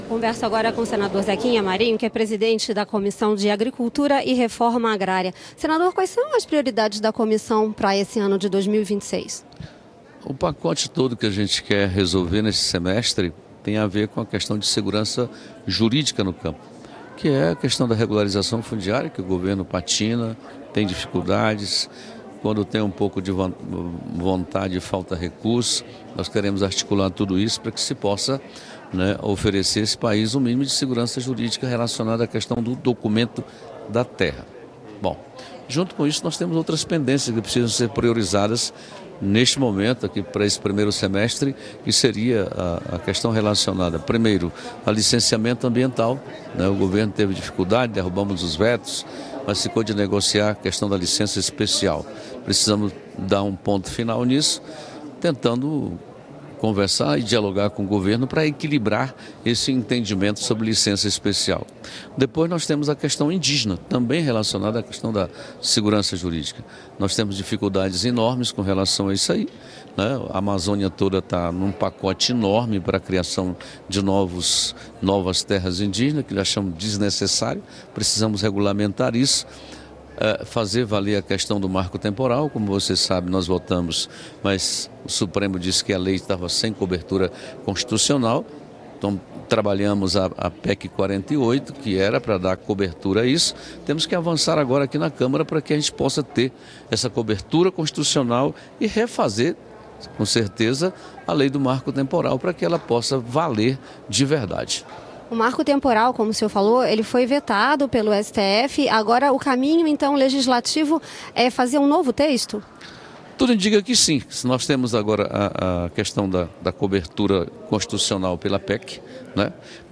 O presidente da Comissão de Agricultura e Reforma Agrária do Senado (CRA), Zequinha Marinho (Podemos-PA), destacou em entrevista concedida nesta segunda-feira (2) quais devem ser as prioridades do colegiado neste ano. Entre elas estão a regularização fundiária, as regras de licenciamento ambiental e a regulamentação do marco temporal para a demarcação de terras indígenas.